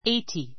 （ ⦣ gh は発音しない）